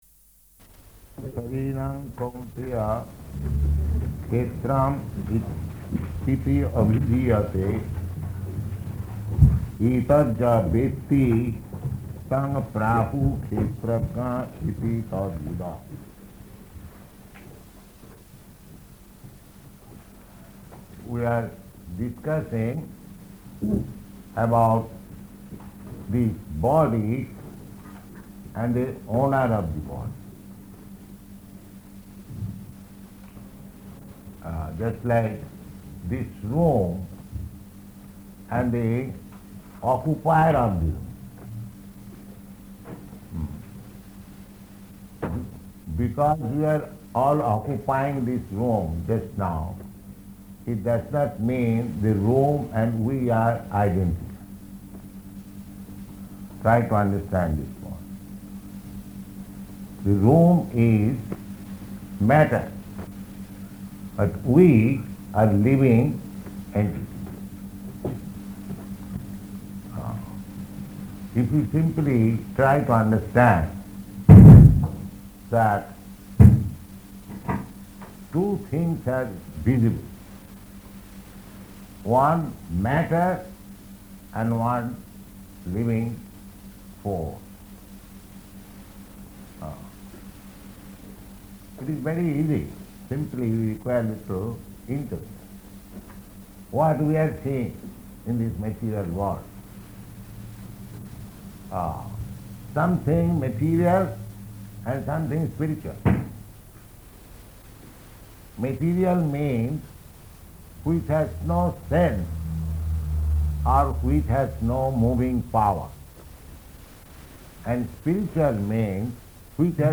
Location: Melbourne